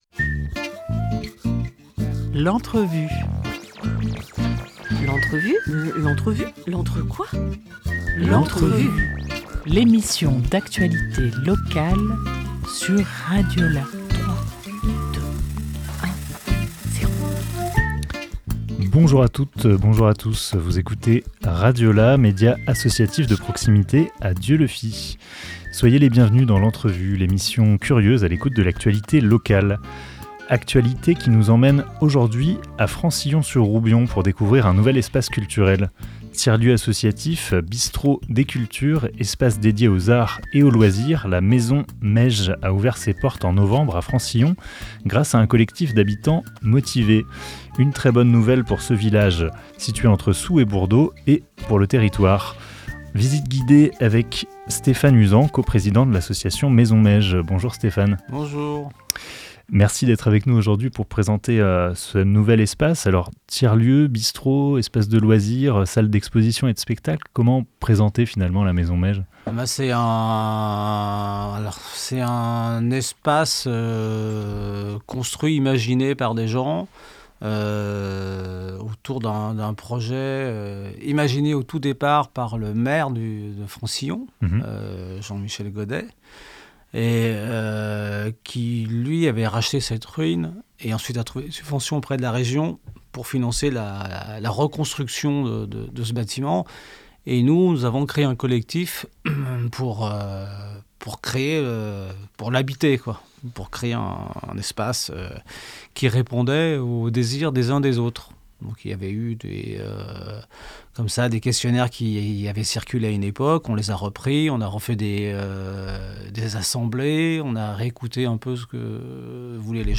3 décembre 2024 11:06 | Interview